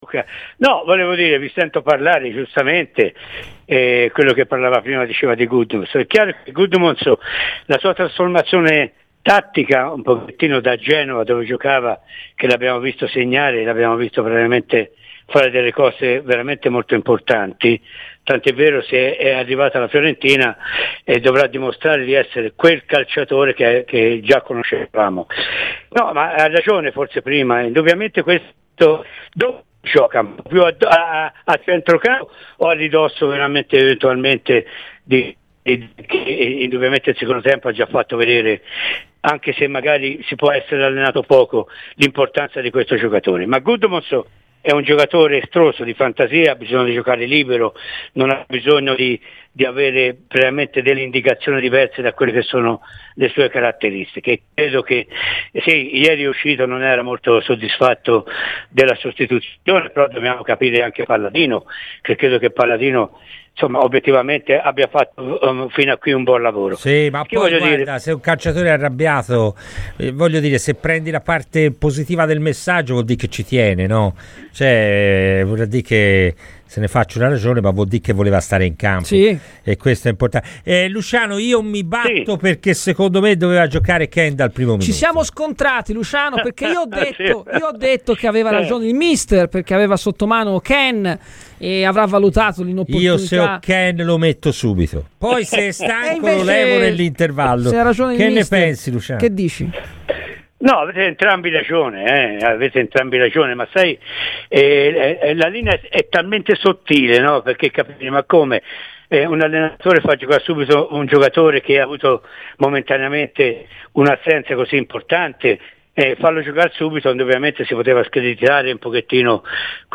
L'ex attaccante viola Luciano Chiarugi è intervenuto a Radio FirenzeViola durante "Palla al centro" per parlare della gara di ieri, a partire dalla prova di Gudmundsson: "Ha avuto una trasformazione tattica rispetto a Genova, ma è estroso, di fantasia e deve giocare libero per esprimere le sue caratteristiche.